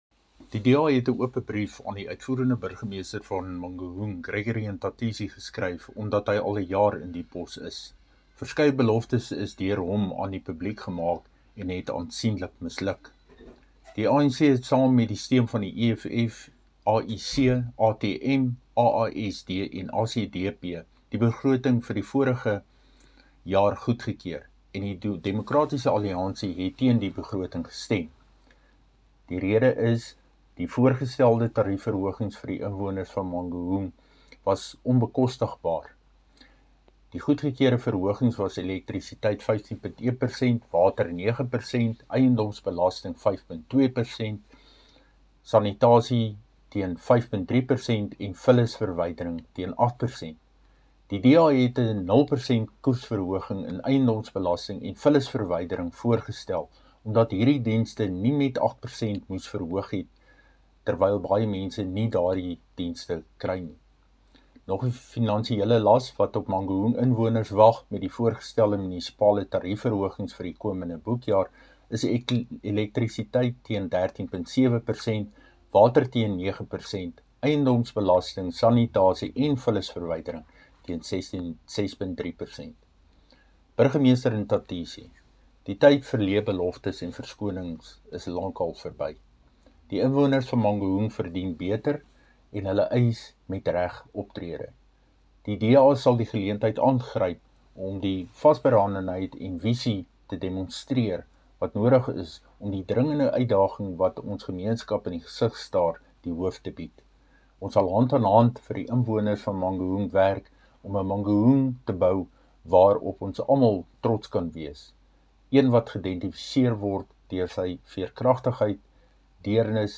Afrikaans soundbites by Cllr Dirk Kotze.